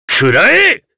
Kain-Vox_Attack4_jp.wav